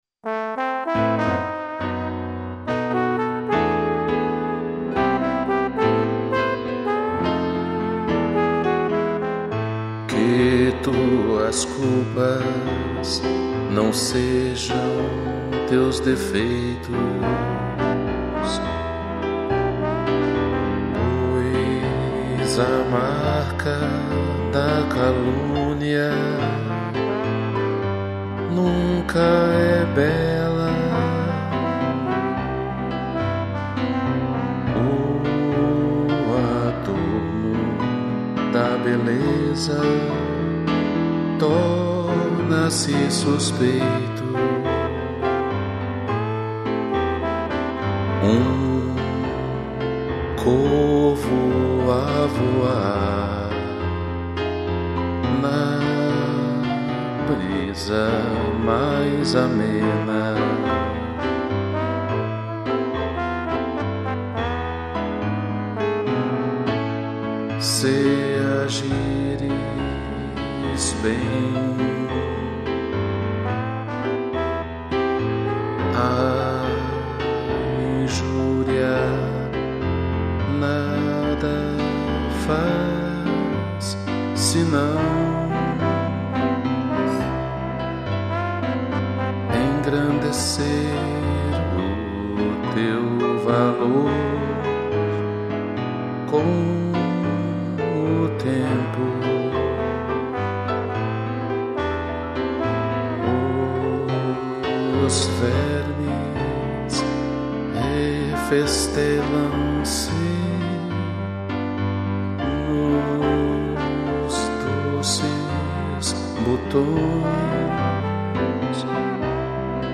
2 pianos e trombone